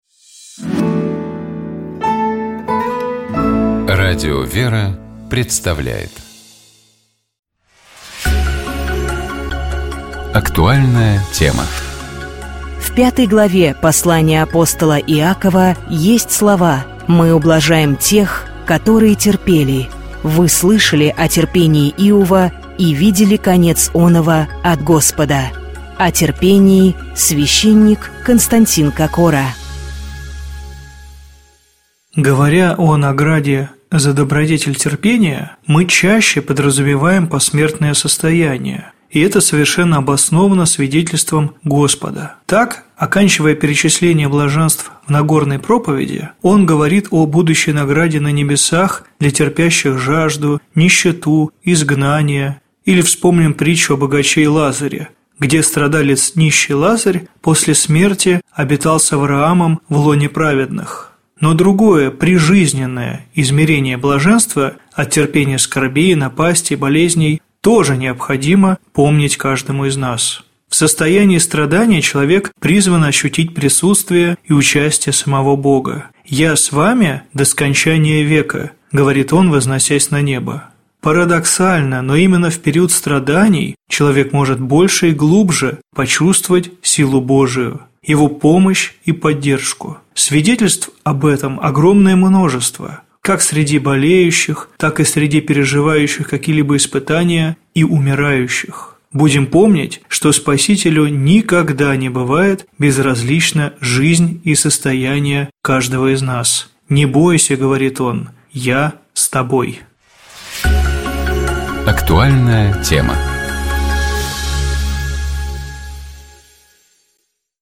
О терпении, — священник